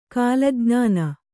♪ kālajñāna